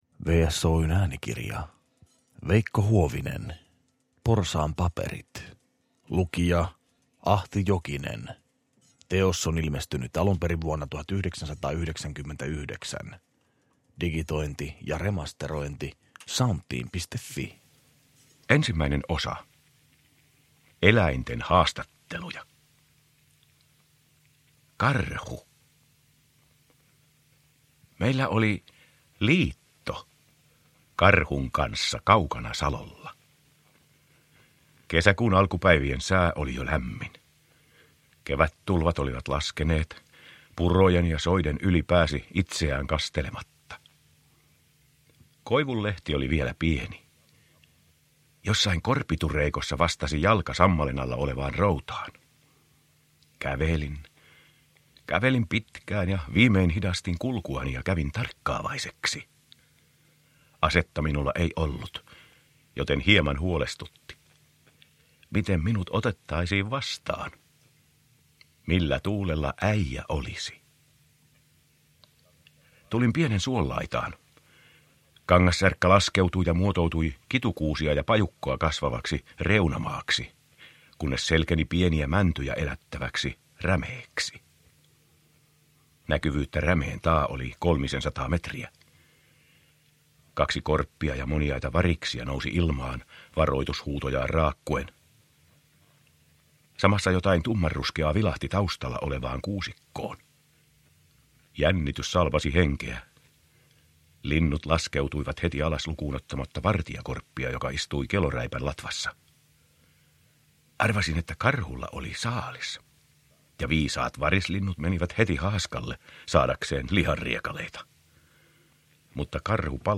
Porsaan paperit – Ljudbok